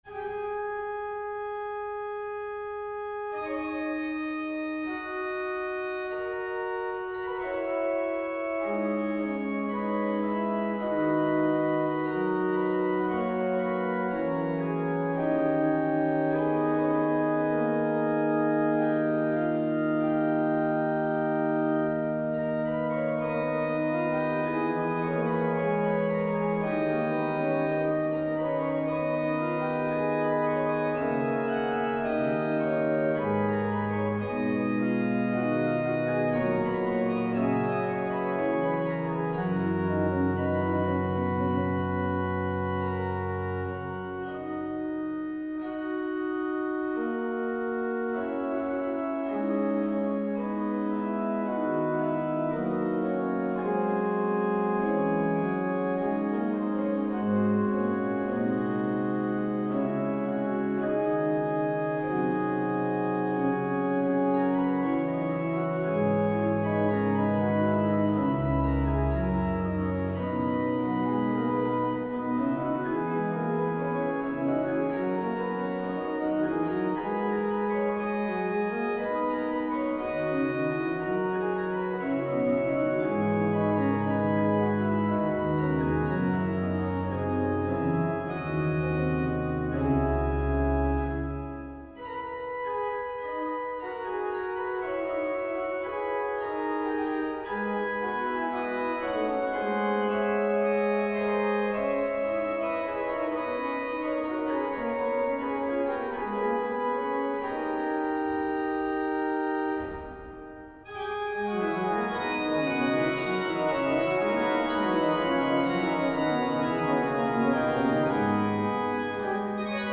Organista